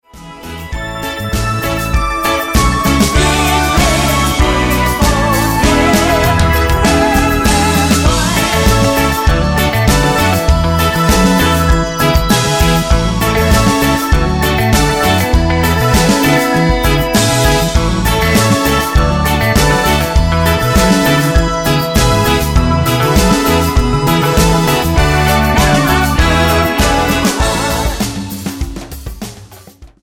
Tonart:A mit Chor